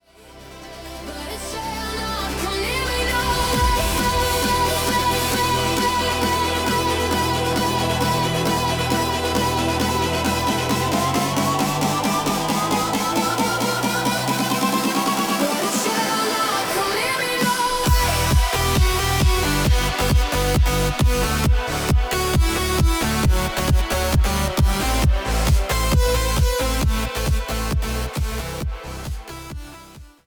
EDM Psalms: Progressive House Worship: